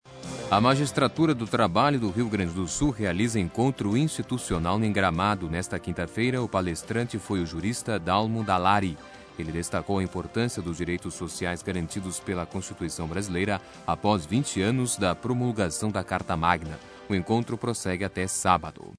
Clique no ícone de áudio, acima, à direita do título, e ouça notícia (19") veiculada em 09/10/2008, no programa Jornal da Noite (dom. a sex. das 23h às 24h) da Rádio Guaíba AM (720 kHz) sobre a participação do jurista Dalmo Dallari, no III Encontro da Magistratura do Trabalho do Rio Grande do Sul.